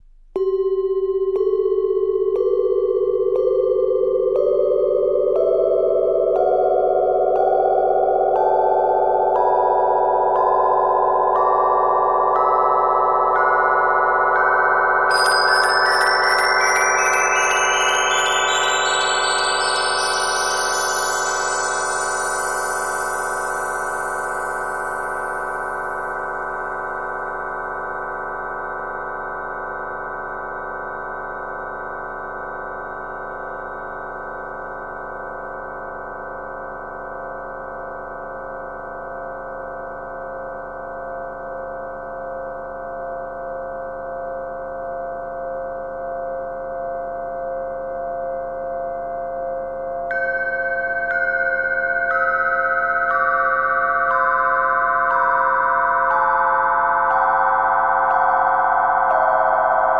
🔊 Exploring Sound Frequencies: 384Hz sound effects free download
🔊 Exploring Sound Frequencies: 384Hz to 10,000Hz 🌌